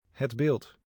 het Bildt (Dutch: [ɦɛd ˈbɪlt]
or [əd ˈbɪlt]) is a former municipality in the province of Friesland in the northern Netherlands; its capital was Sint Annaparochie.